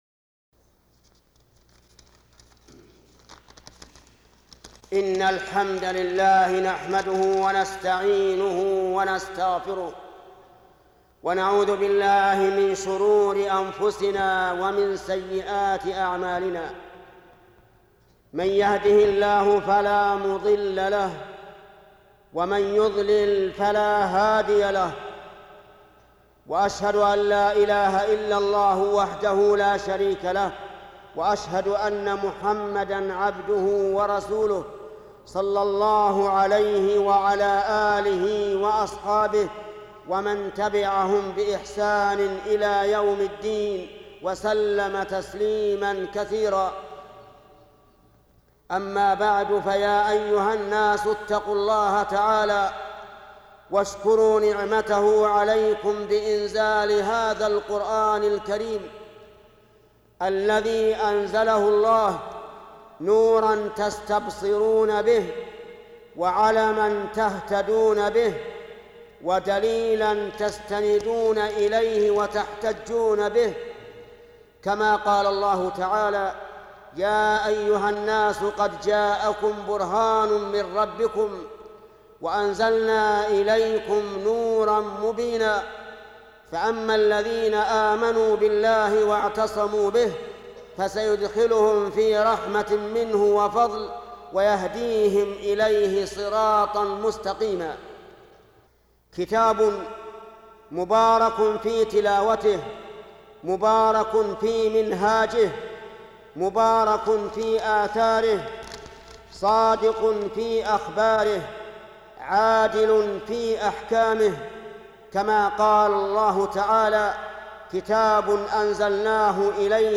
خطبة جمعة لشيخ محمد بن صالح العثيمين بعنوان خيركم من تعلم القرآن وعلمه